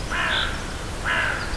Stimme einer Krähe, täuscht tiefe Frequenzen vor.
Abb. 04: Ausschnitt, die tiefste Fequenz der Krähe liegt bei ungefähr 600 Hz.
Krähe
kraehe-kurz.wav